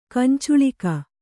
♪ kancuḷika